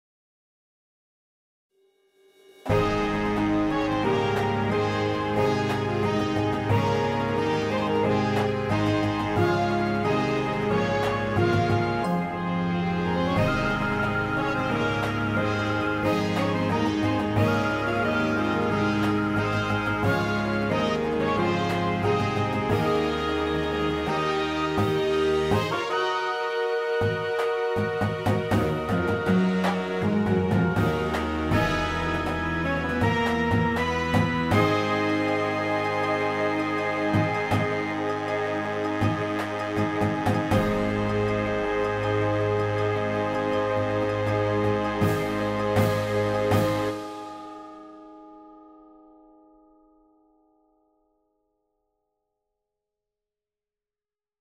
Instrumentation:
Piccolo
Flute
Bass Clarinet
Alto Sax
Trumpet 1, 2, 3
Trombone 1, 2
Tuba
Snare Line
Marimbas
Chimes
Synth 1 (Harp)
Synth 2 (Strings)
Timpani